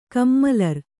♪ kammalar